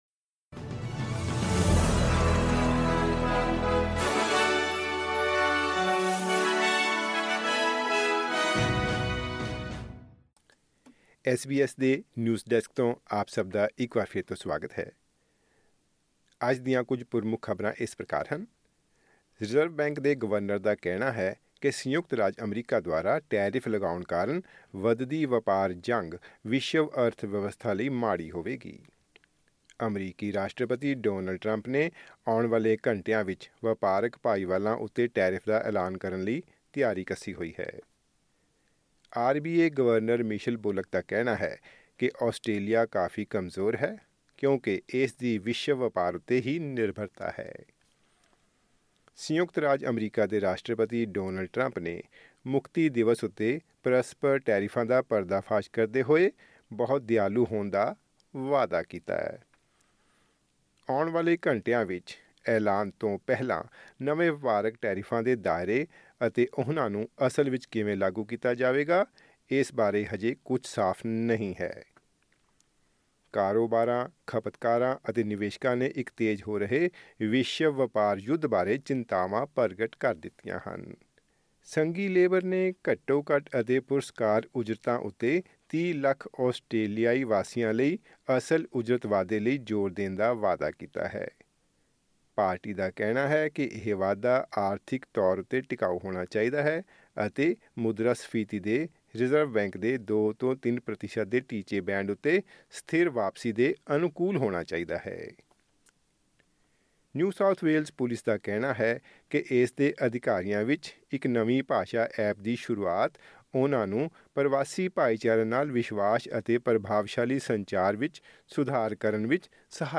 ਖਬਰਨਾਮਾਂ: ਸੰਯੁਕਤ ਰਾਜ ਦੁਆਰਾ ਲਾਏ ਜਾ ਰਹੇ ਟੈਰਿਫਾਂ ਕਾਰਨ ਵਿਸ਼ਵ ਪੱਧਰ ਹੋਵੇਗਾ ਪ੍ਰਭਾਵਤ: ਰਿਜ਼ਰਵ ਬੈਂਕ